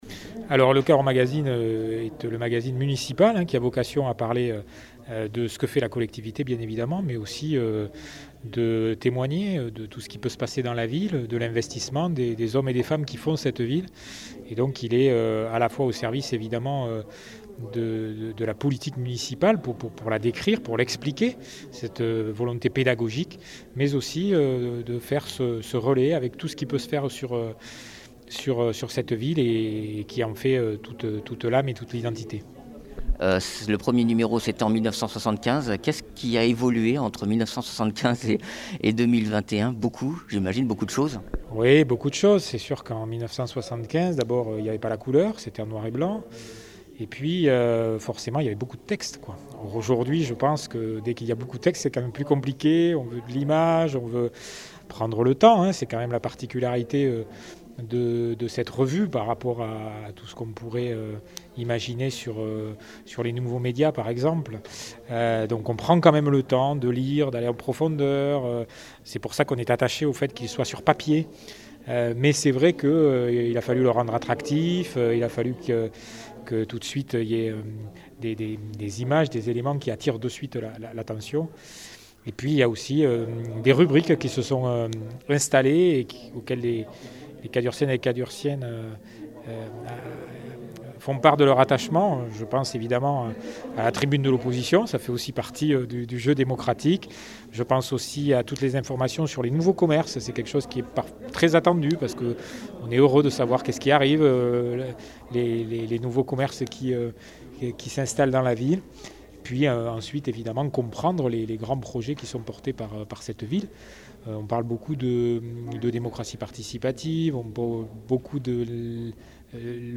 Interviews
Invité(s) : Jean Marc Vayssouze-Faure, Maire de Cahors et directeur de la publication